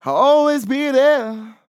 Categories: Vocals
MAN-LYRICS-FILLS-120bpm-Am-1.wav